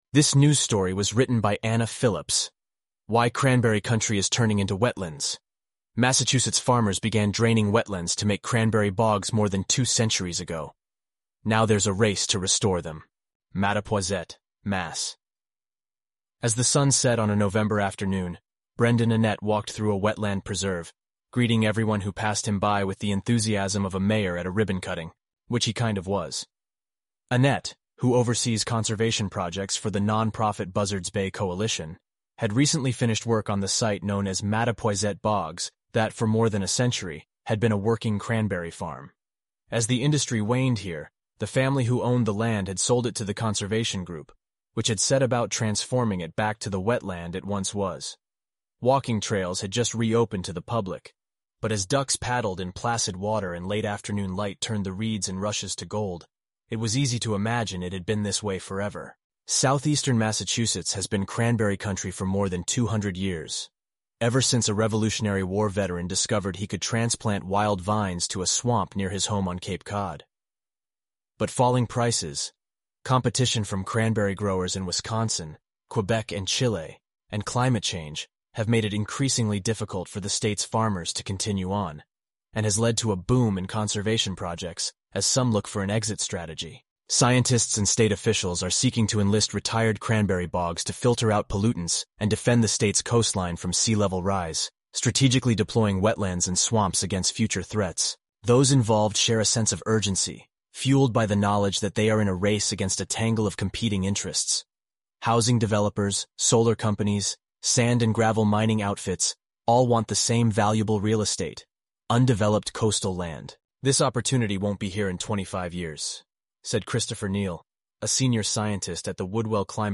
eleven-labs_en-US_Josh_standard_audio.mp3